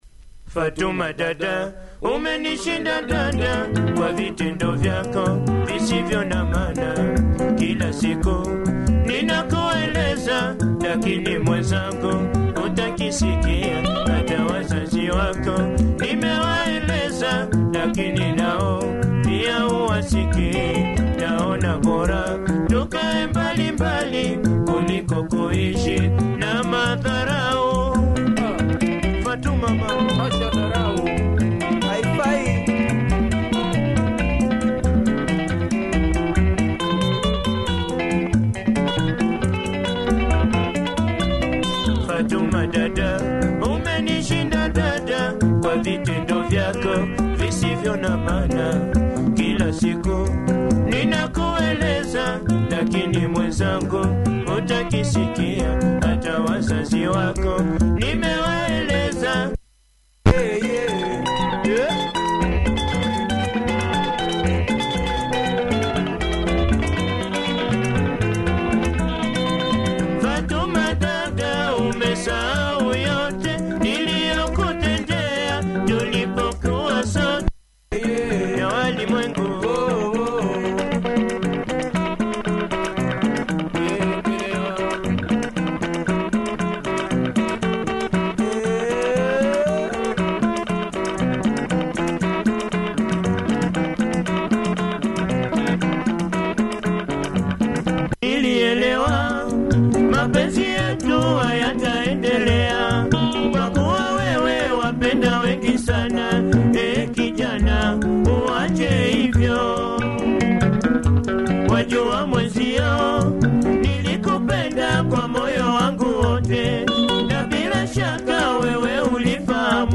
Nice breakdowns! Clean copy check audio! https